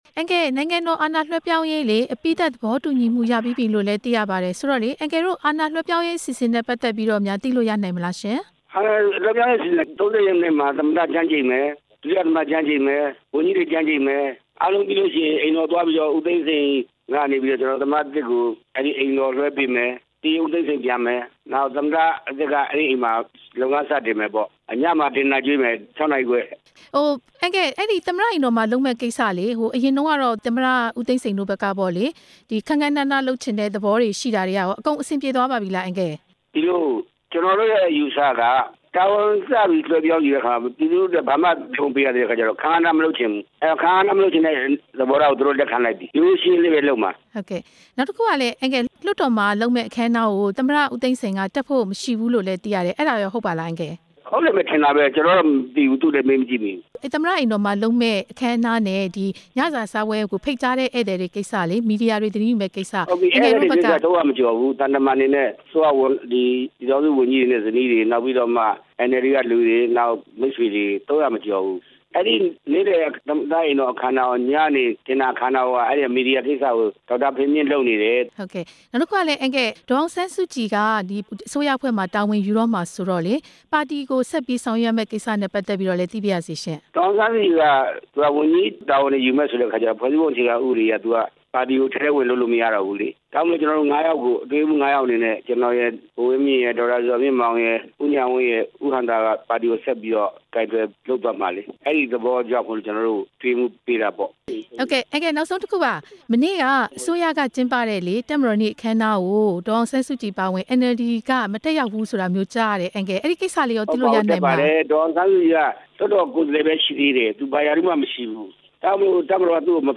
သမ္မတသစ်ကို အာဏာ လွှဲပြောင်းရေး အစီအစဉ် ဦးဝင်းထိန်နဲ့ ဆက်သွယ်မေးမြန်း ချက်